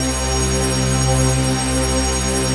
RAVEPAD 07-LR.wav